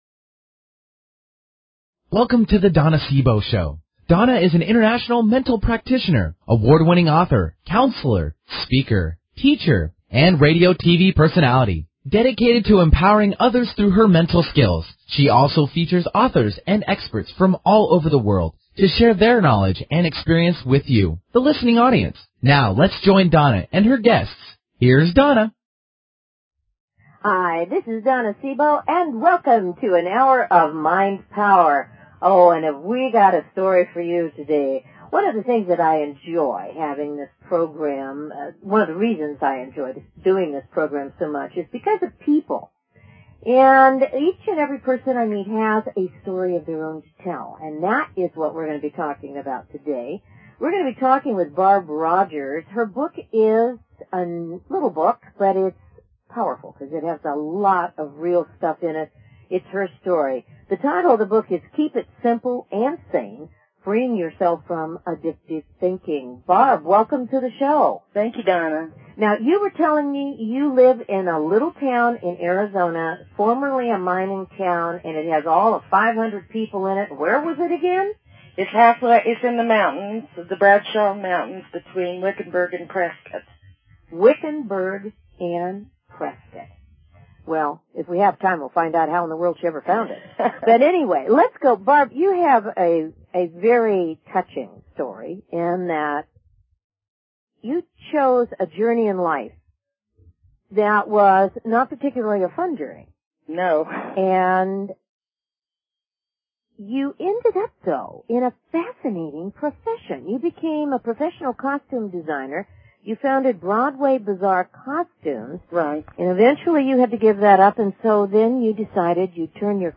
Talk Show Episode, Audio Podcast
Callers are welcome to call in for a live on air psychic reading during the second half hour of each show.